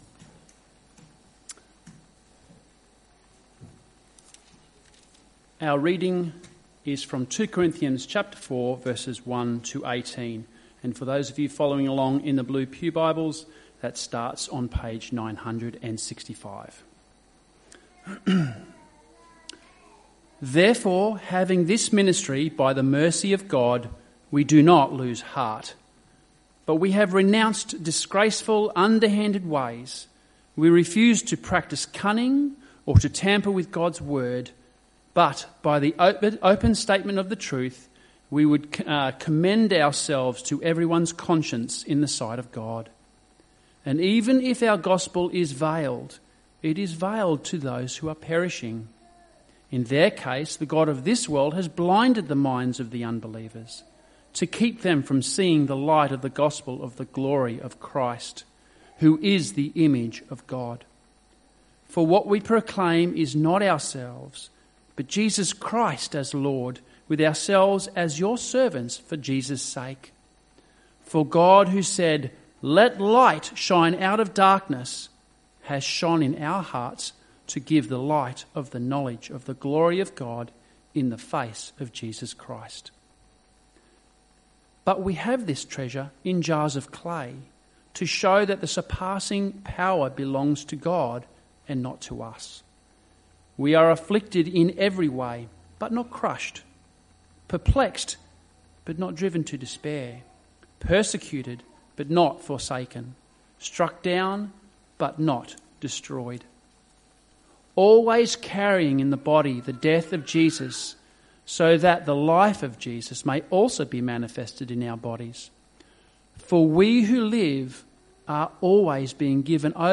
Sermons
Evening Service